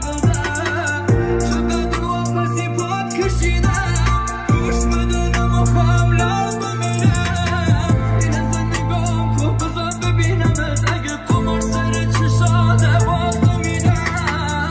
محلی